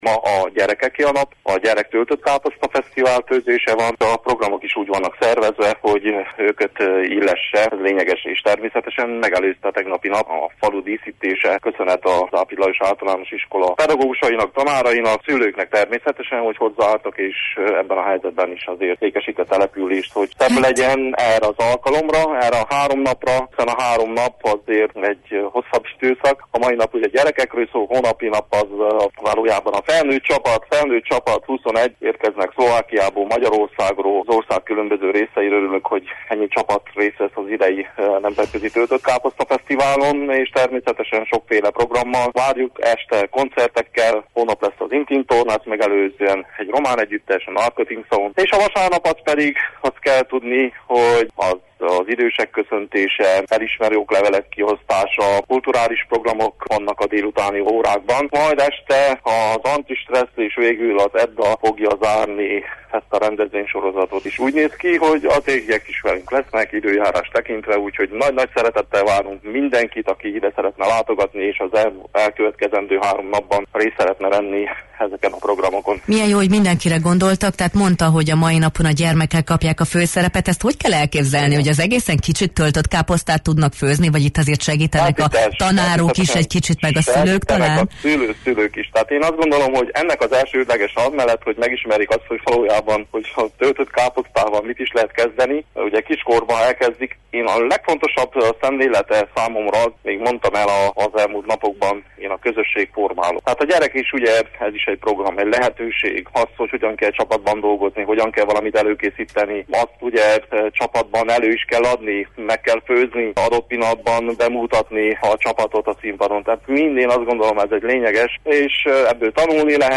Nyágrus László, Parajd község polgármestere hívott meg bennünket a rendezvényre: